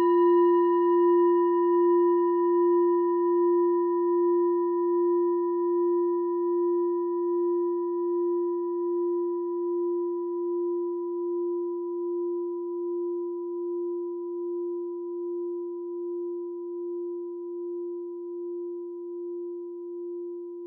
Kleine Klangschale Nr.4
Sie ist neu und ist gezielt nach altem 7-Metalle-Rezept in Handarbeit gezogen und gehämmert worden.
(Ermittelt mit dem Minifilzklöppel)
Oktaviert man diese Frequenz 37mal, hört man sie bei 229,43 Hz, das ist auf unserer Tonleiter nahe beim "B".
kleine-klangschale-4.wav